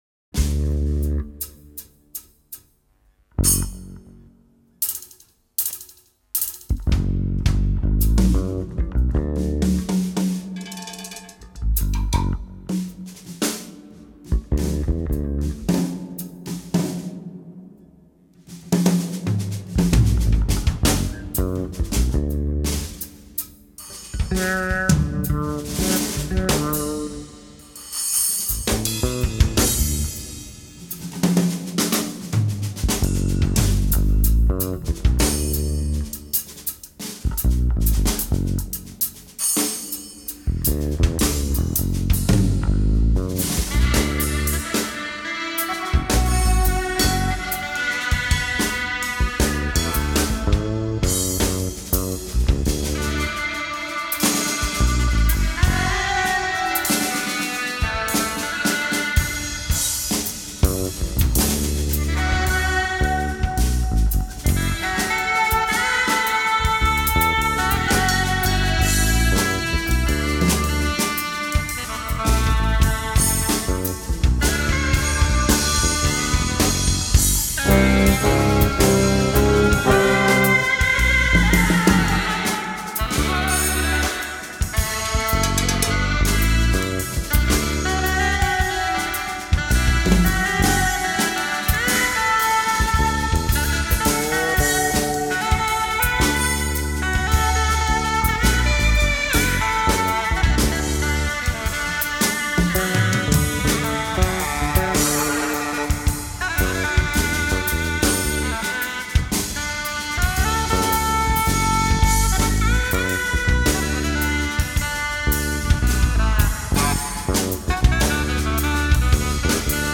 drums / percussions
bass
woodwinds Écouter un extrait